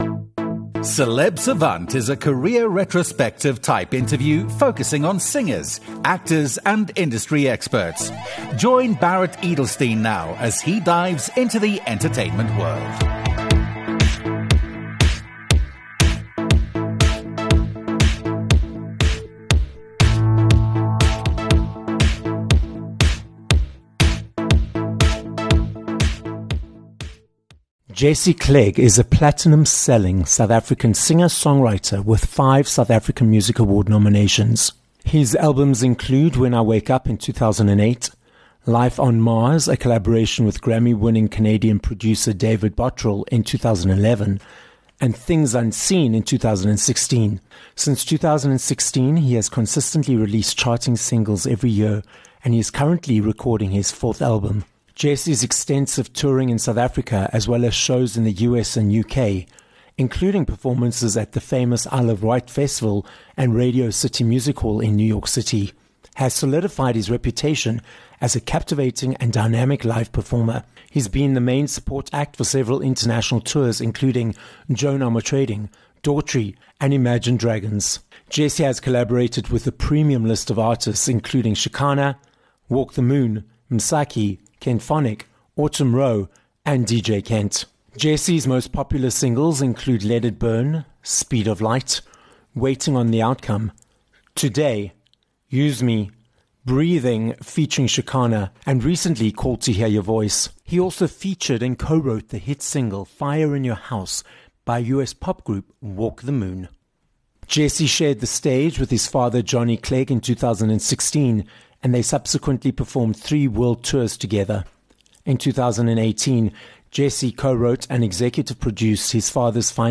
Jesse Clegg - a 5 x South African Award nominee singer, songwriter, and producer - joins us live in the studio on this episode of Celeb Savant. Jesse explains how he was a ‘passenger’ growing up in the music world, as the son of the late Johnny Clegg, how he transitioned to become a professional artist, and how his creative process has changed over time. This episode of Celeb Savant was recorded live in studio at Solid Gold Podcasts, Johannesburg, South Africa.